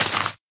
grass1.ogg